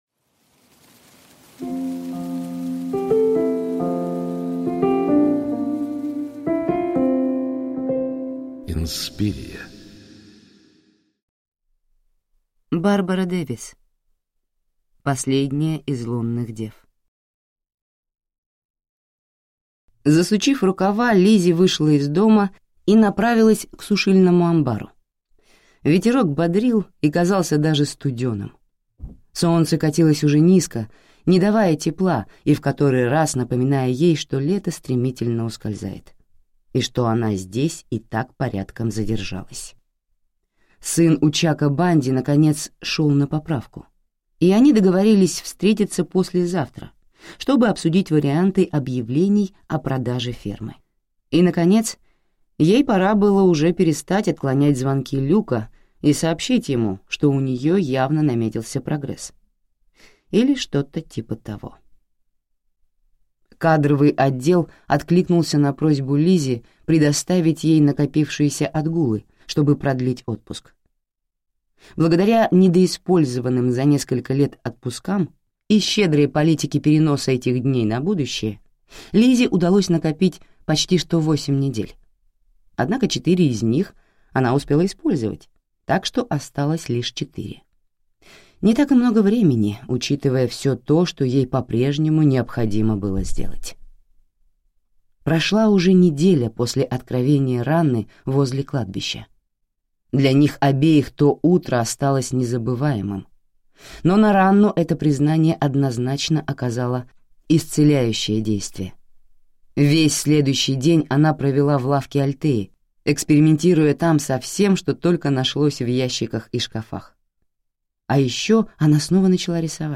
Аудиокнига Последняя из Лунных Дев | Библиотека аудиокниг